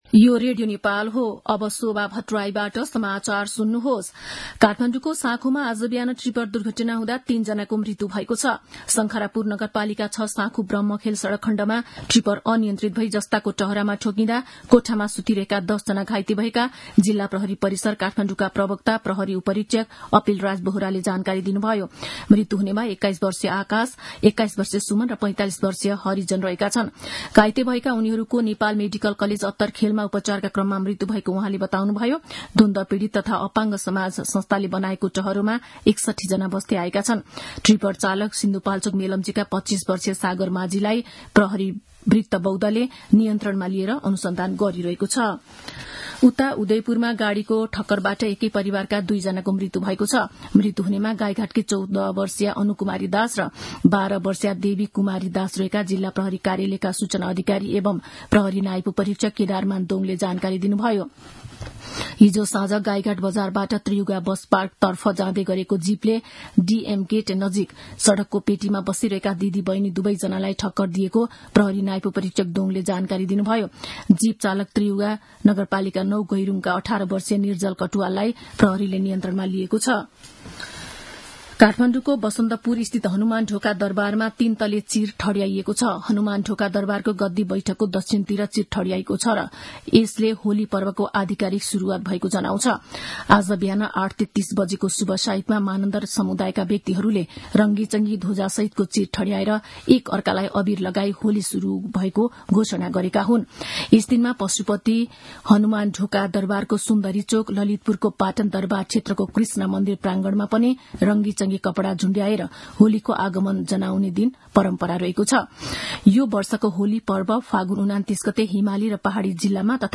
मध्यान्ह १२ बजेको नेपाली समाचार : २४ फागुन , २०८१